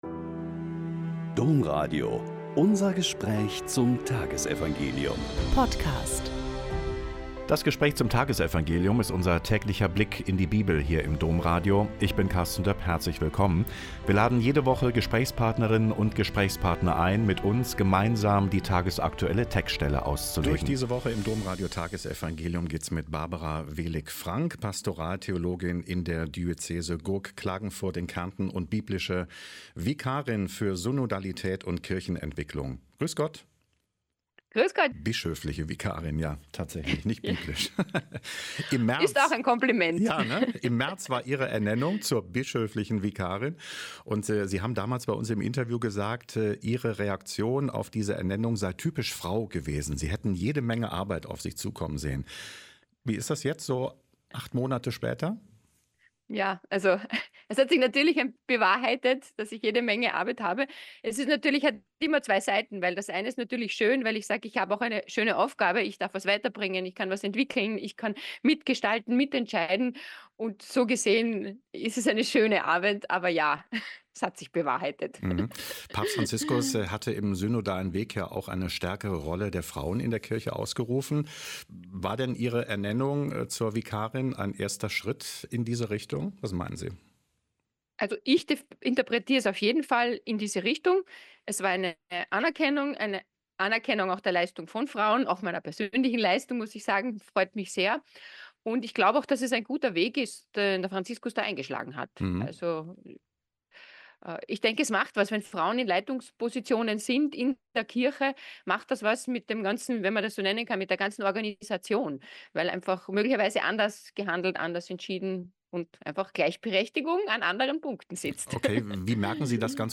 Lk 19,1-10 - Gespräch